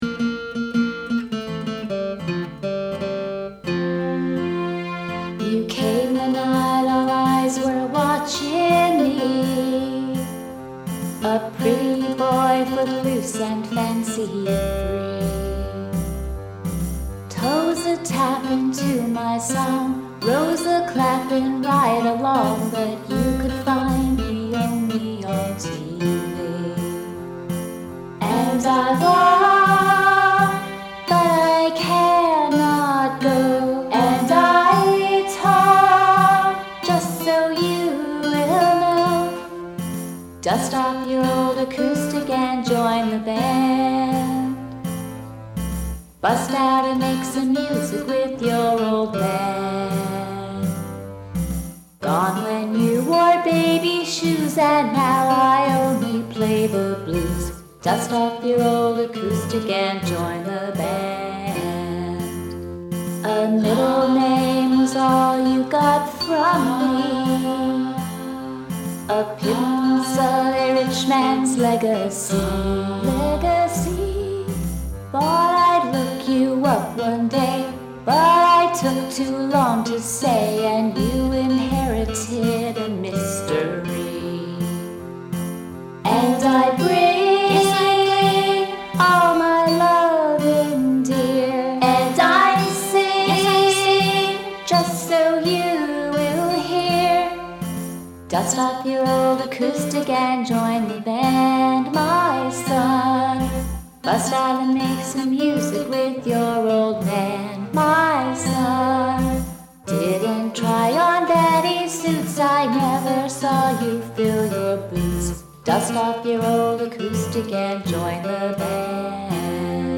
When the song is over, the patrons return to talking and joking.
I was influenced by the country vibe of many of his tunes, particularly “Listen to the Band.”
Over the past week, I have redone the vocals and added acoustic guitar throughout.
F major
• “Loved it! I especially like the end where you make it seem like it’s a recording in front of an audience. Very clever! I have ‘my son’ running through my head.”
• “Fun song! I like the ‘live’ touches at the end.”